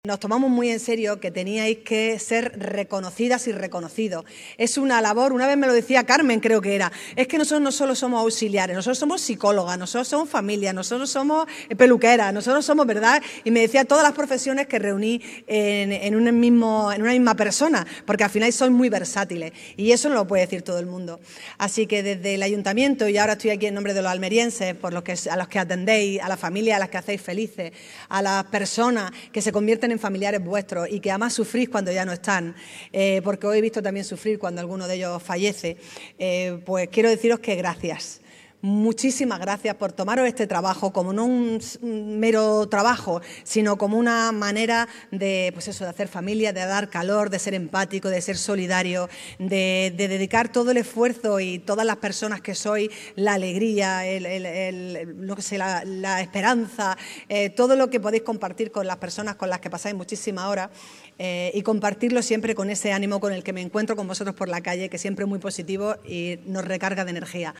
En una gala celebrada en el Teatro Cervantes, la alcaldesa ha destacado la capacidad de las profesionales de “escuchar, acompañar, entender y apoyar a los mayores para que se sientan únicos y especiales”
ALCALDESA-GALA.mp3